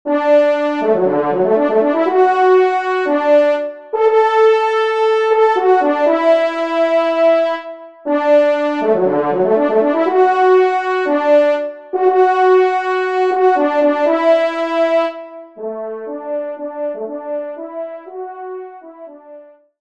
Genre :  Divertissement pour quatre Trompes ou Cors
Pupitre 1° Trompe / Cor